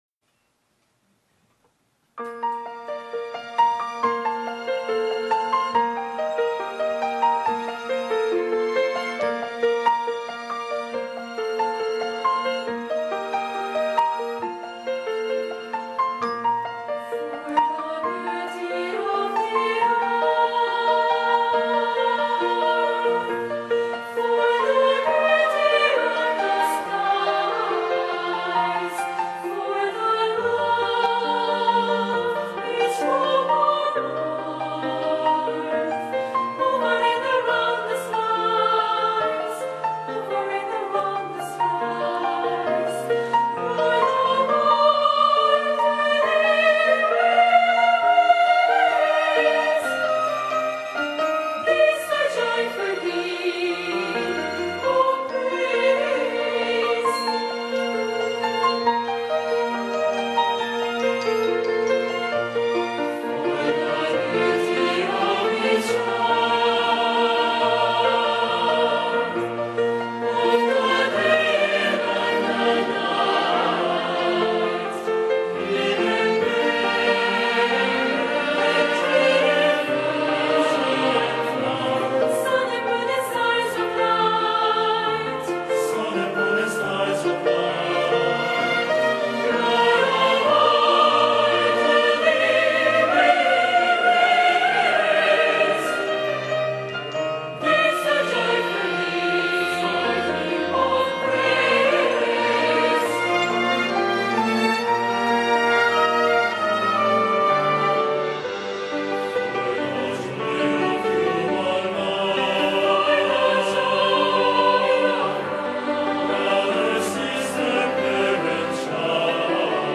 GoodNewsTV Program 성가공연 For the Beauty of the Earth
스페인어로 ‘감사’라는 뜻을 지닌 이름을 가진 ‘그라시아스 합창단’이 선사하는 성가 공연은 가사와 멜로디 속에 담긴 감동과 영감을 그대로 전달하며, 하나님에 대한 무한한 감사와 찬양을 돌린다.